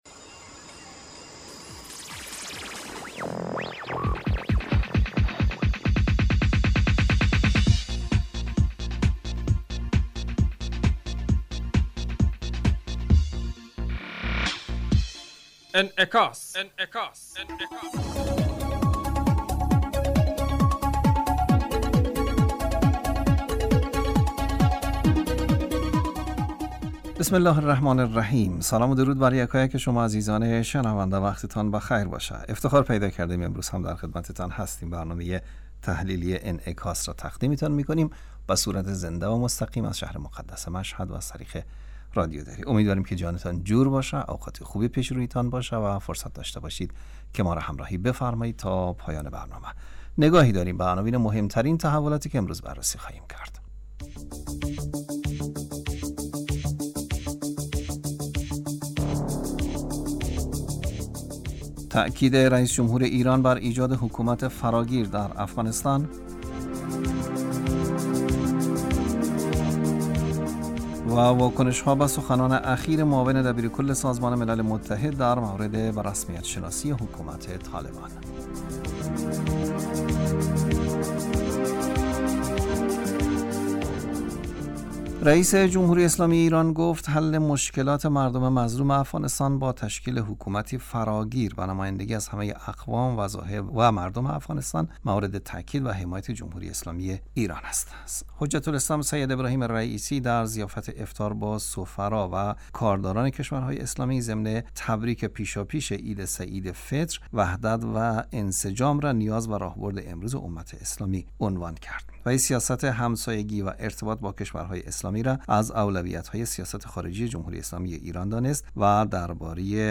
برنامه انعکاس به مدت 30 دقیقه هر روز در ساعت 05:55 بعد ظهر بصورت زنده پخش می شود. این برنامه به انعکاس رویدادهای سیاسی، فرهنگی، اقتصادی و اجتماعی مربوط به افغانستان و تحلیل این رویدادها می پردازد.